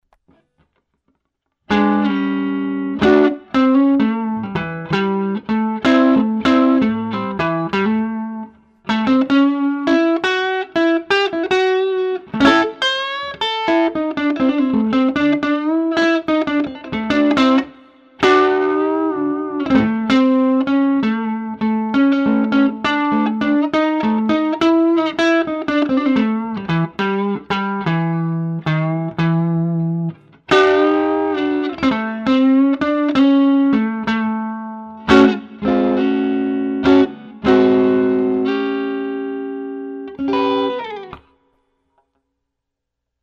Blue Tone Reamp
ai_ten2_blue_tone_reamp.mp3